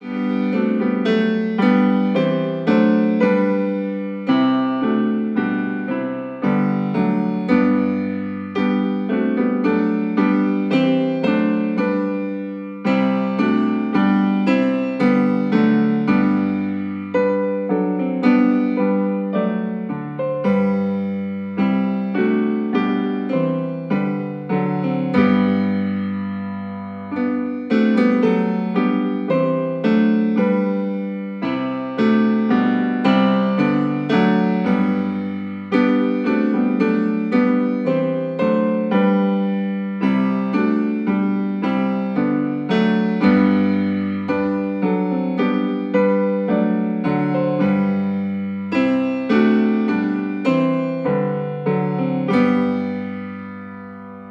interprétés dans une atmosphère chaleureuse et raffinée.